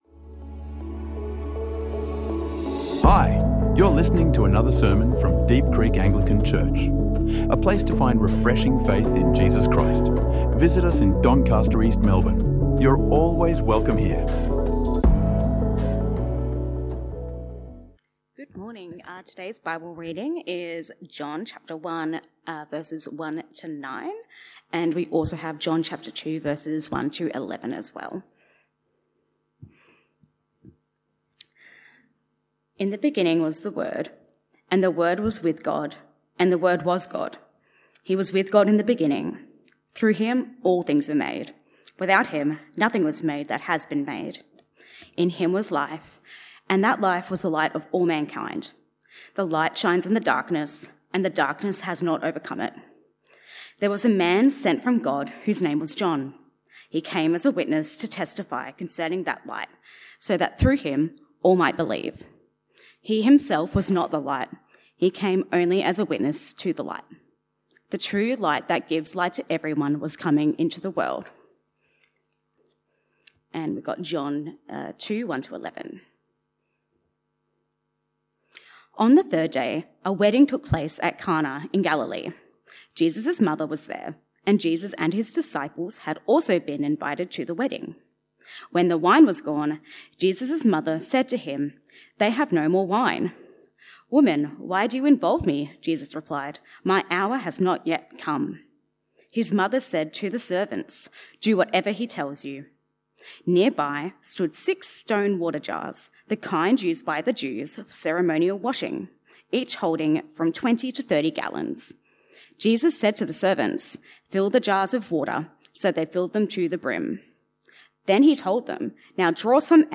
Advent sermon on waiting for Christ in past, present and future through John 1 and Cana, and our call to open our hands to his transforming grace.